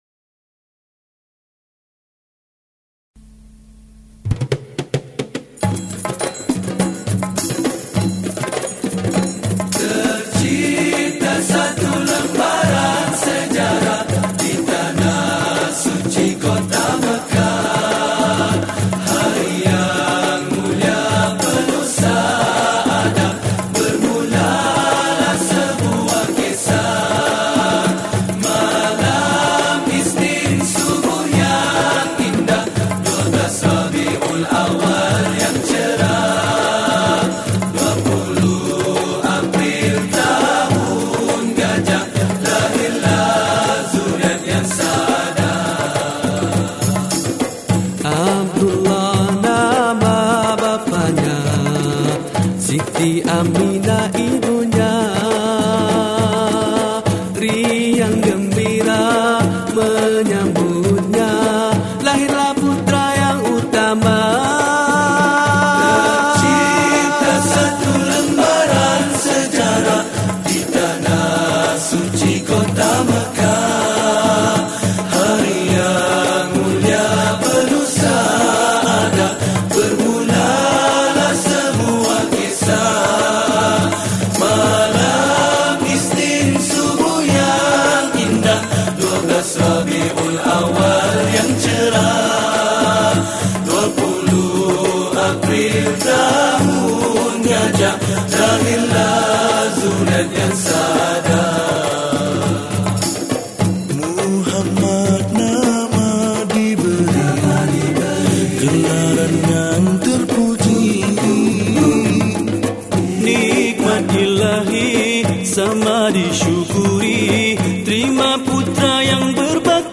Nasyid Songs
Skor Angklung